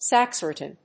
音節sac・ris・tan 発音記号・読み方
/sˈækrɪstən(米国英語)/